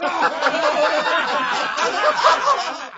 crowd_laugh2.wav